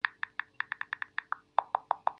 Звук клавиатуры на айфоне